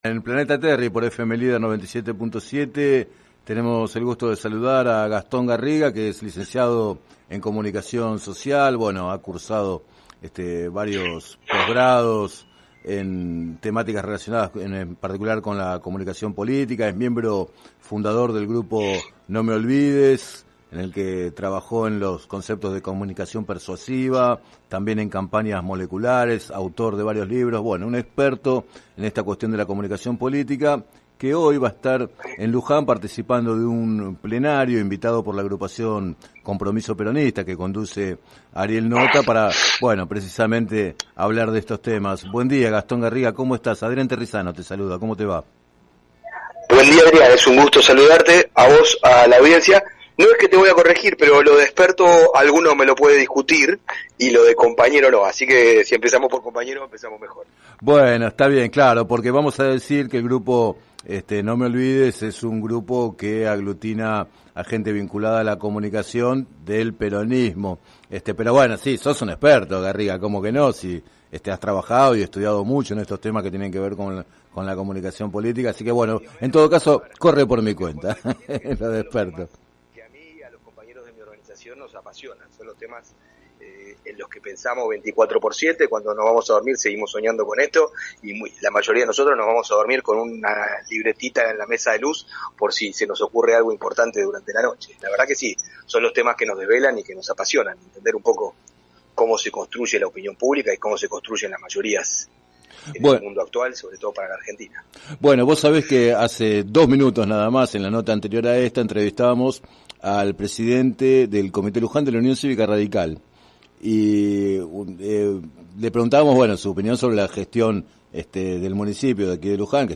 En declaraciones al programa “Planeta Terri” de FM Líder 97.7